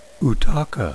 .wav ("ooh-TAW-kuh") are any of more than 30 species of Lake Malawi "haps," all of which are currently classified in one of two genera, Copadichromis and Mchenga, that share (with some variations) the same distinctive feeding ecology.